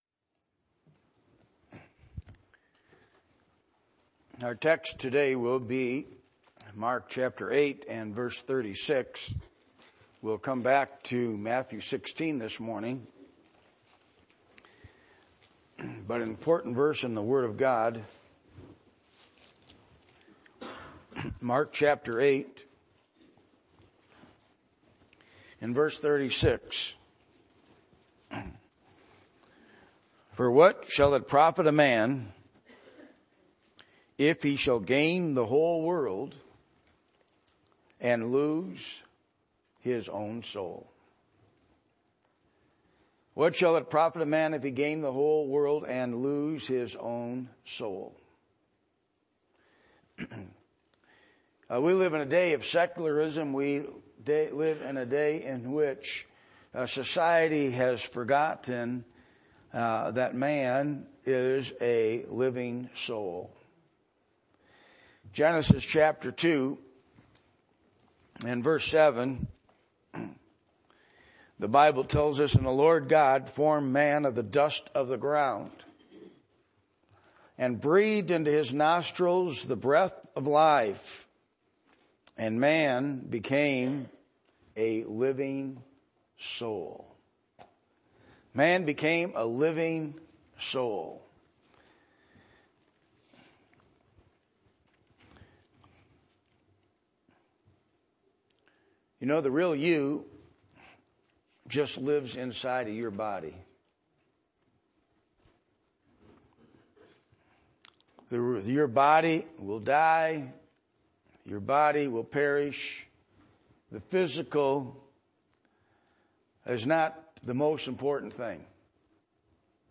Matthew 16:1-28 Service Type: Sunday Morning %todo_render% « Change of Master